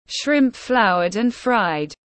Tôm lăn bột tiếng anh gọi là shrimp floured and fried, phiên âm tiếng anh đọc là /ʃrɪmp flaʊər ænd fraɪd/
Shrimp floured and fried /ʃrɪmp flaʊər ænd fraɪd/
Shrimp-floured-and-fried-.mp3